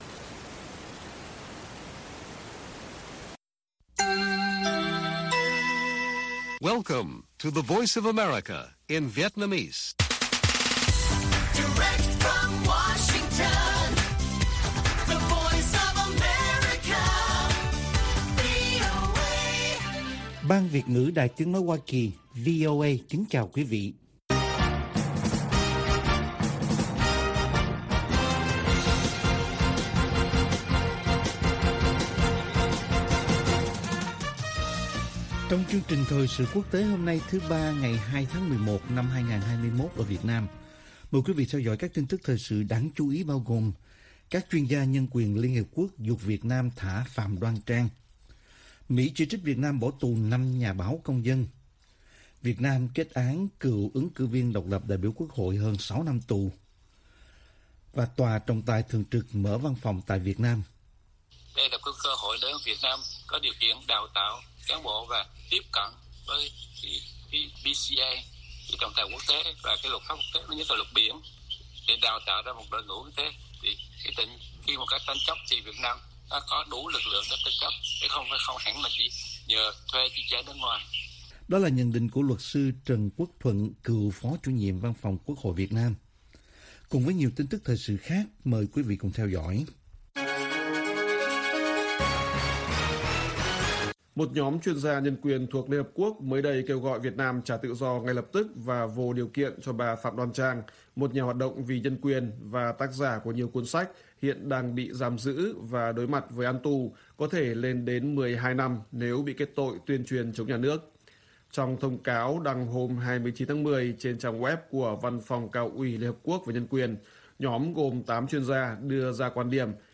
Bản tin VOA ngày 2/11/2021